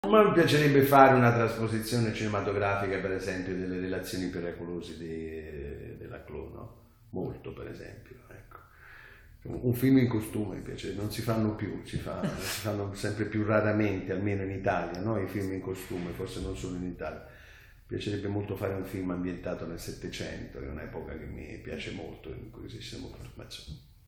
Intervista.